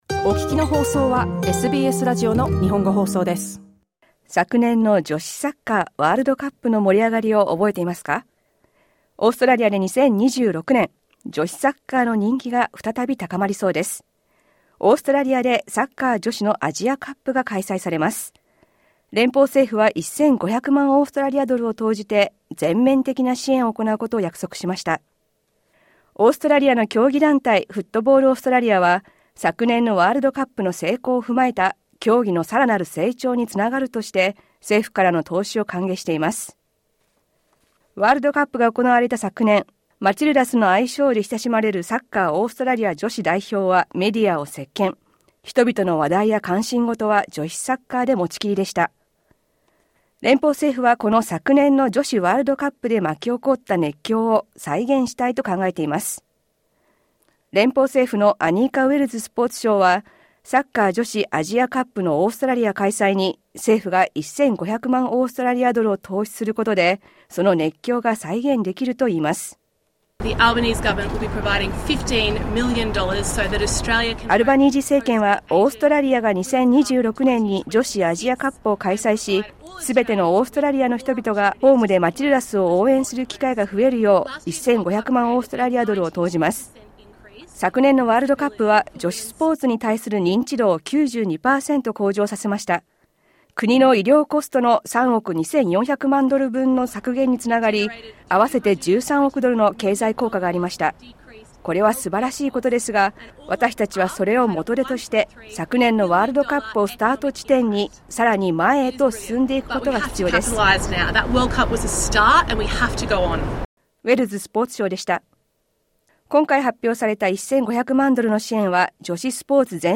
詳しくは音声リポートからどうぞ。